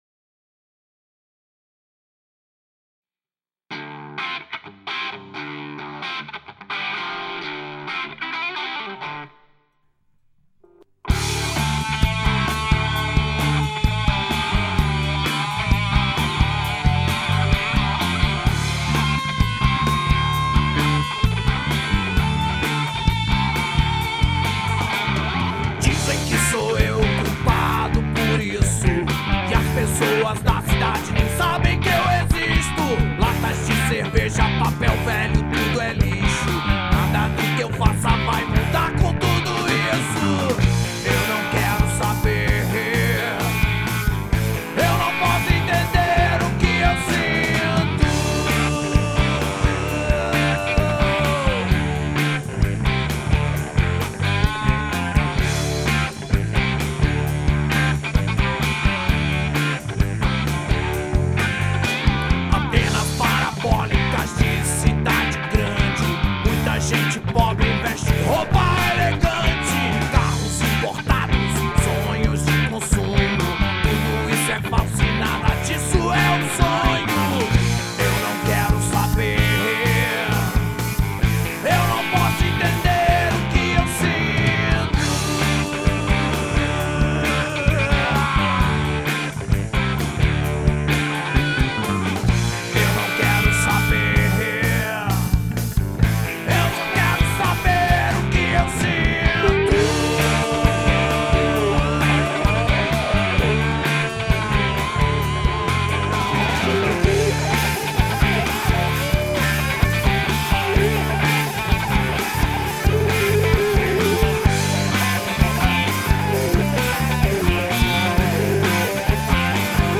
Rock Nacional